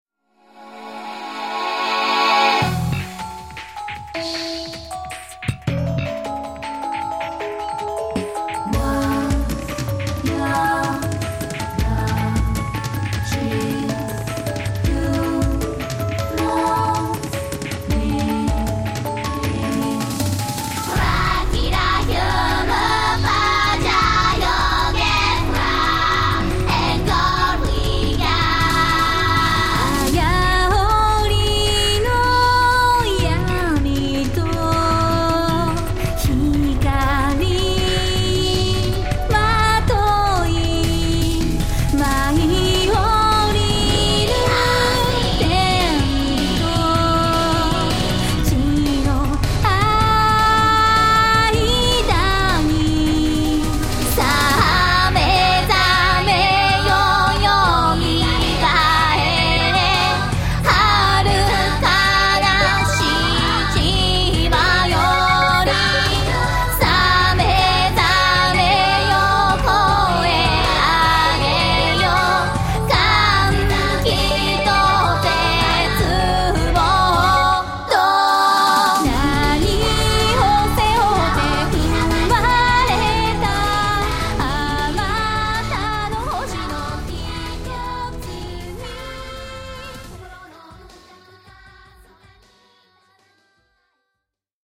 この曲の最大の聴き所は、CDにしか収録されていない後半のとんでもない変拍子 の部分です。